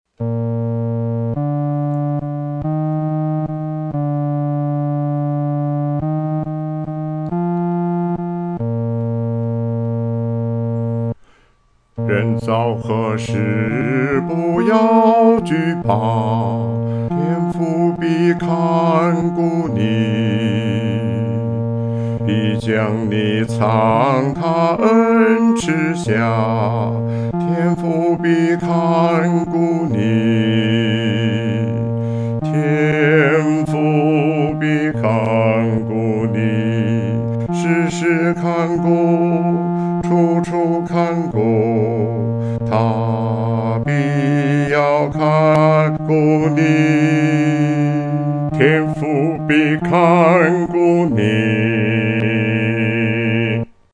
独唱（第四声）
天父必看顾你-独唱（第四声）.mp3